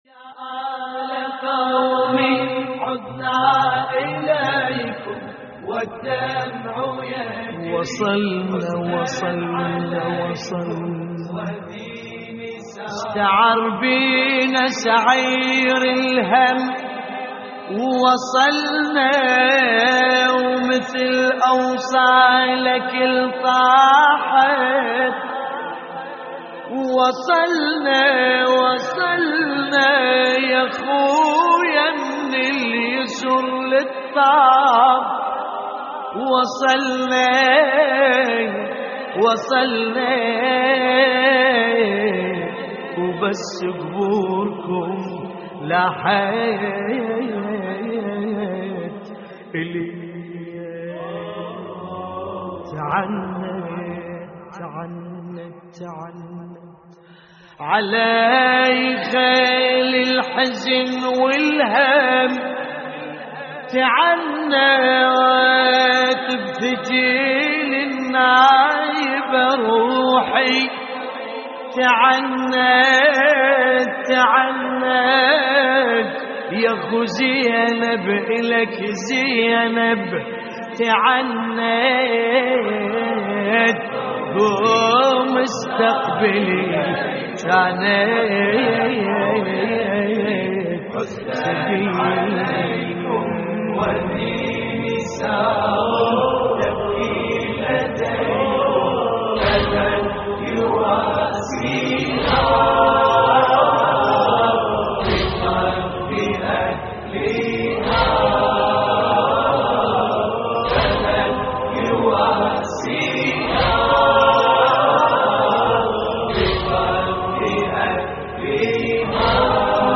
تحميل : يا آل قومي عدنا إليكم والدمع يجري حزناً عليكم / الرادود باسم الكربلائي / اللطميات الحسينية / موقع يا حسين
موقع يا حسين : اللطميات الحسينية يا آل قومي عدنا إليكم والدمع يجري حزناً عليكم - استديو لحفظ الملف في مجلد خاص اضغط بالزر الأيمن هنا ثم اختر (حفظ الهدف باسم - Save Target As) واختر المكان المناسب